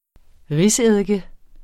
Udtale [ ˈʁis- ]